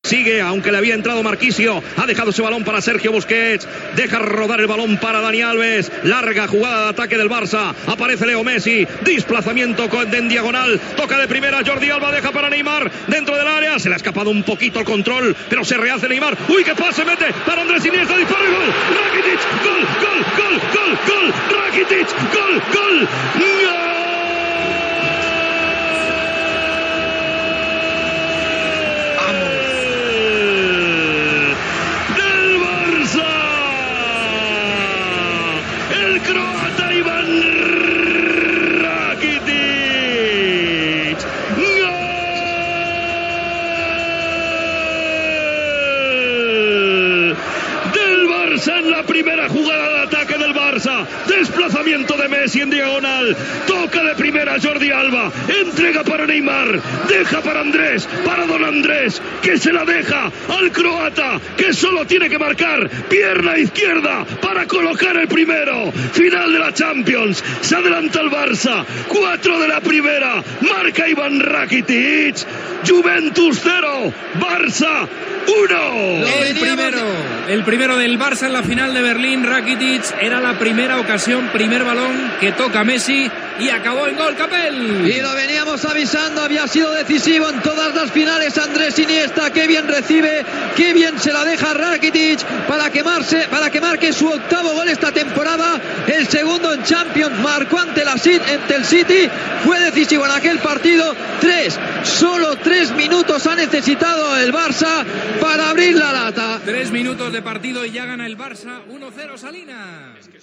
Transmissió de la final de la Copa d'Europa de futbol masculí, des de l'Olympiastadion de Berlín, del partit entre el Futbol Club Barcelona i la Juventus.
Narració del gol de Rakitić.
Esportiu